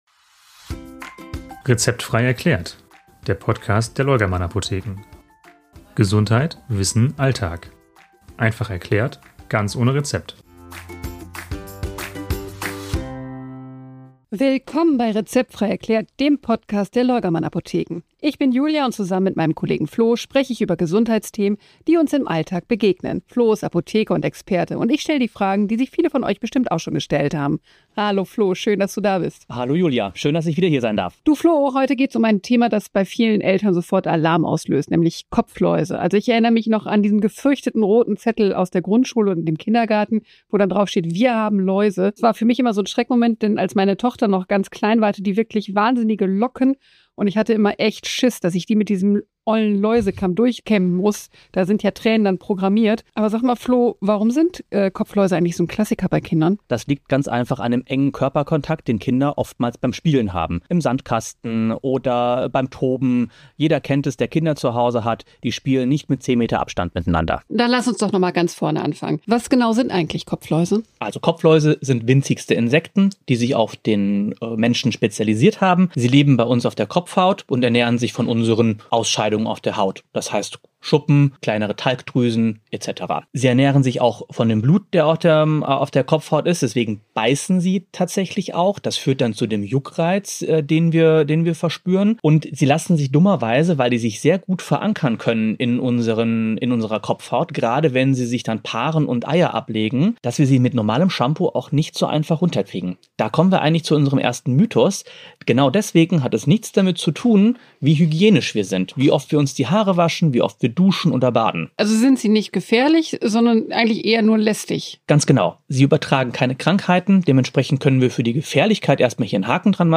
Ihr erfahrt, warum Läuse nichts mit mangelnder Hygiene zu tun haben, wie ansteckend sie wirklich sind, was im Alltag sinnvoll ist und wann man besser zum Arzt gehen sollte. Außerdem räumen wir mit alten Mythen auf – vom Kahlscheren bis zu wilden Hausmitteln. Eine Folge für alle, die sich im Ernstfall einfach sicherer fühlen möchten – ruhig erklärt, alltagsnah und mit dem Blick aus der Apotheke.